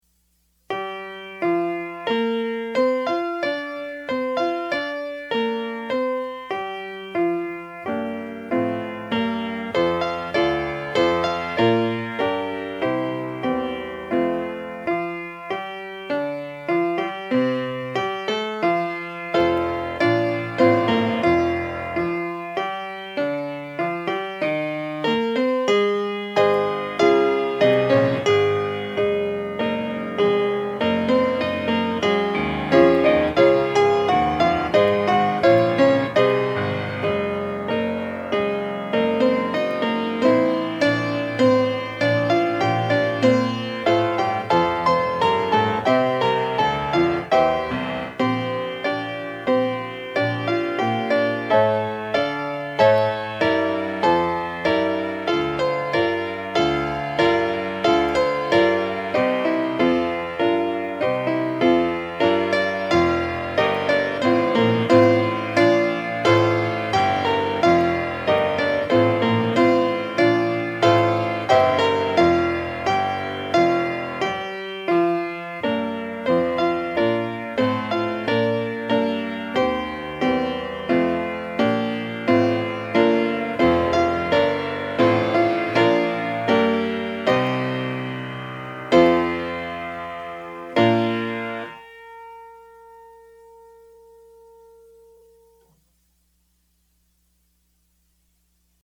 PIANO SOLO Sacred Music, Piano Solo, Prelude, & Postlude
DIGITAL SHEET MUSIC - PIANO SOLO